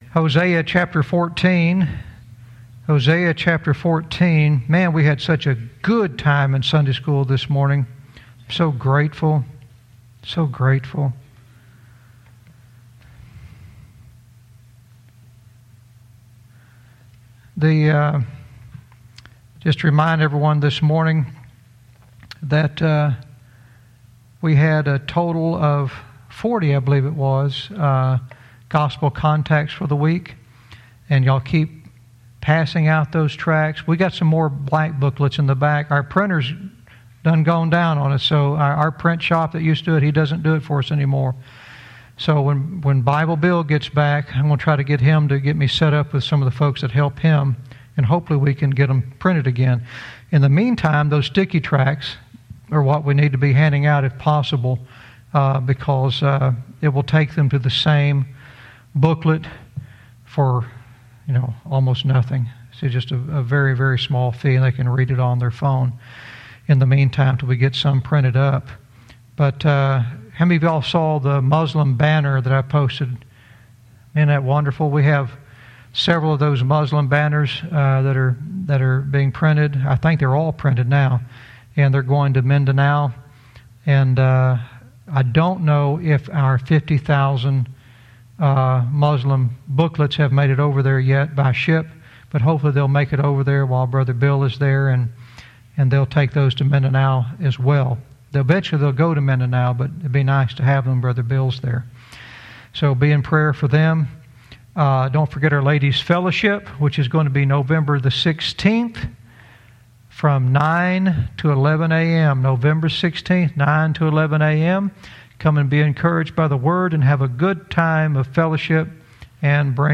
Verse by verse teaching - Hosea 14:3 "Words of Deliverance"